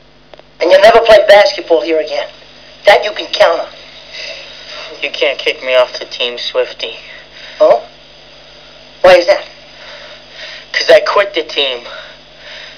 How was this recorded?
Here are some wav sounds taken from the film